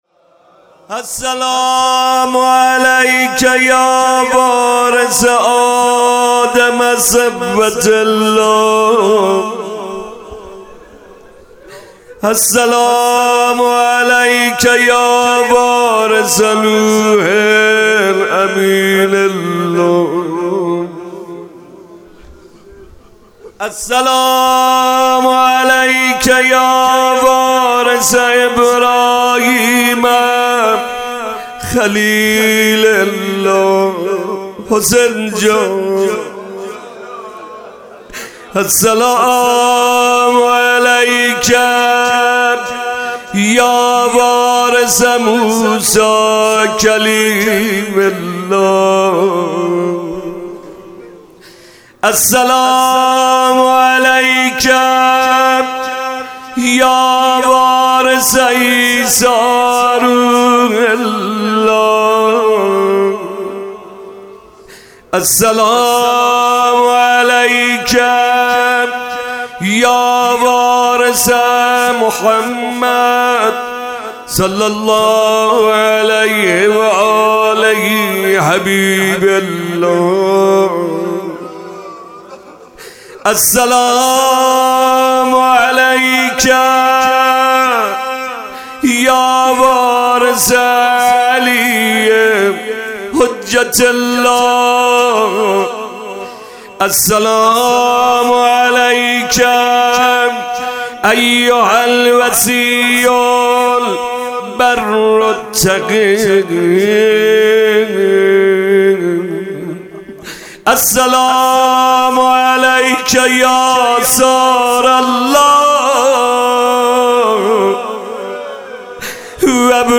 شب عید فطر98 - قرائت زیارت امام حسین علیه السلام در روز عید فطر